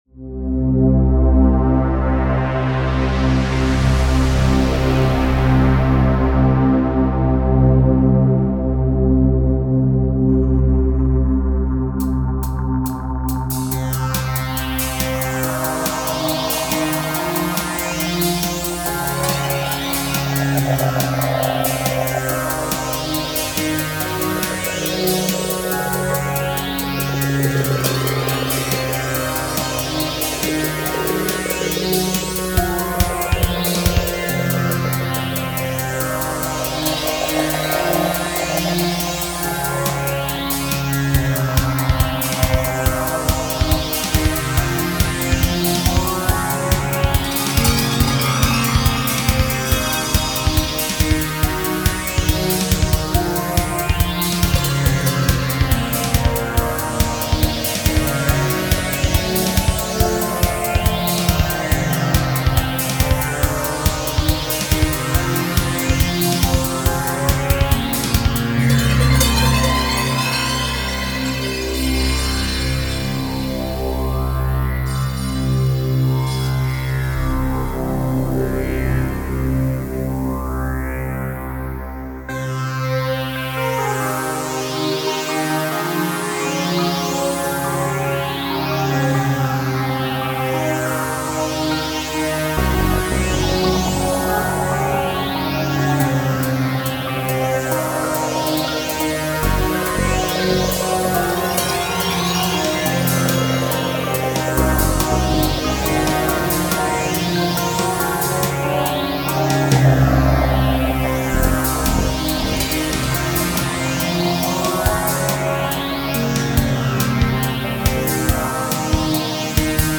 Chillout
140bpm